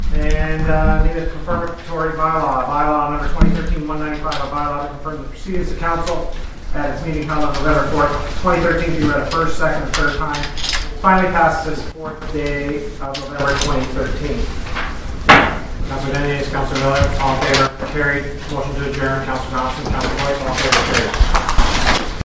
Council met last night for a speedy to vote on the deal: